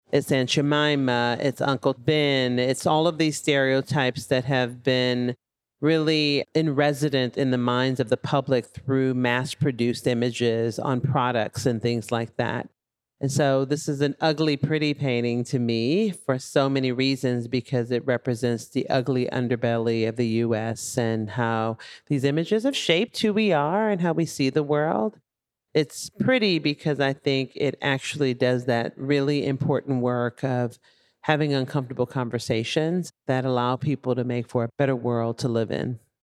Listen as they describe the artwork in their own words.
at Art For Tomorrow in Venice